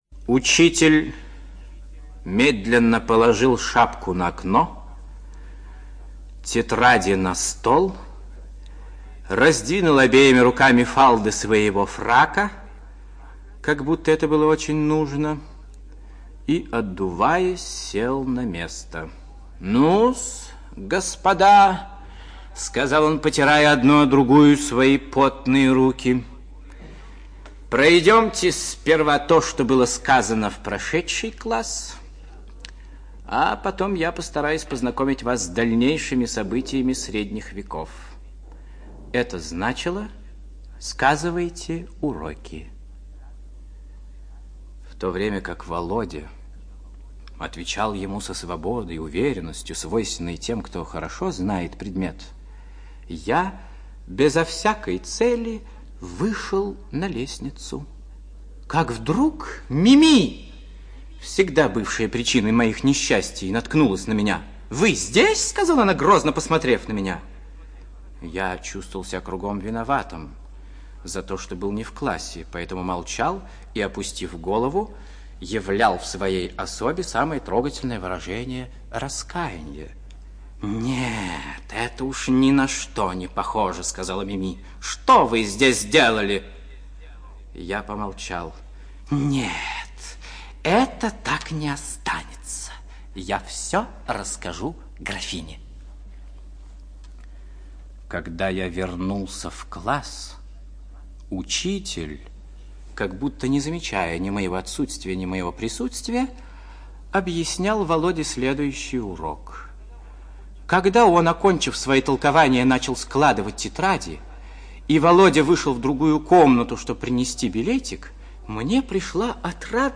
ЧитаетКонсовский А.